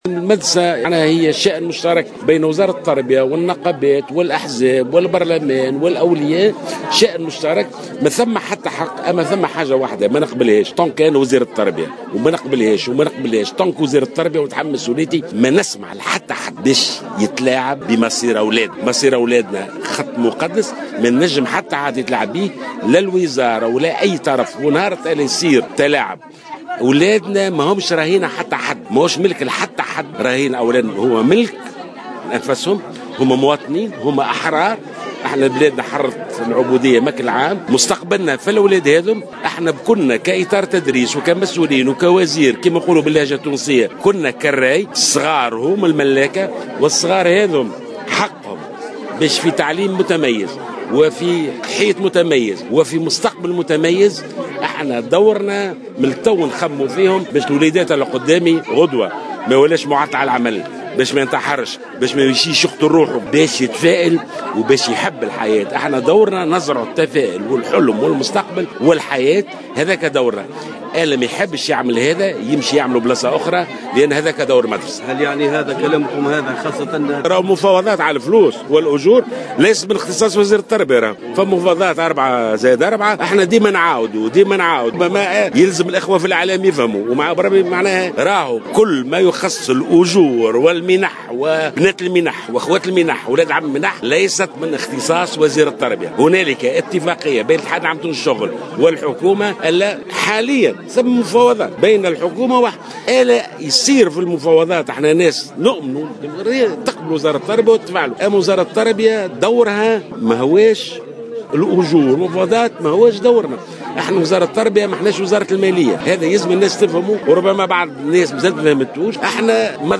أكد وزير التربية ناجي جلول في تصريح لمراسلة الجوهرة أف أم اليوم السبت 12 سبتمبر 2015 خلال زيارة قام بها الى مدرستين بعين طبرنق والقليم بنابل استعدادا للعودة المدرسية أنه لن يسمح بوصفه وزير تربية لأي أحد بالتلاعب بمصير التلاميذ.